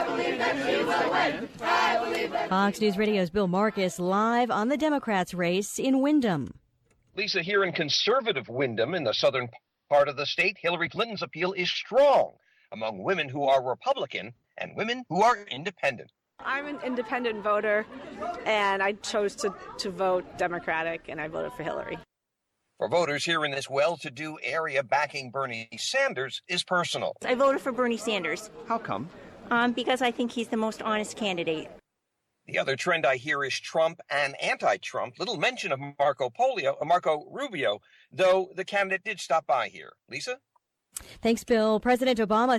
(WINDHAM, NH) FEB 9 – FOX NEWS RADIO, LIVE, 2PM –